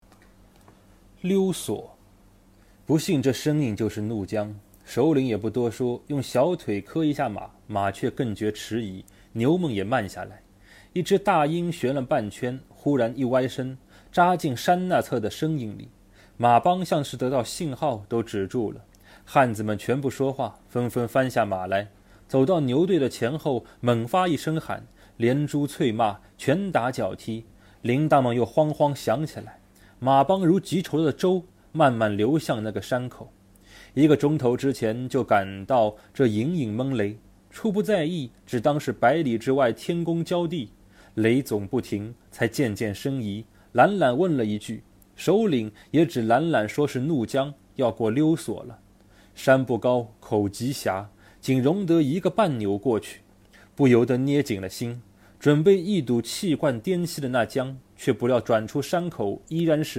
九年级语文下册 7《溜索》男声高清朗诵（音频素材）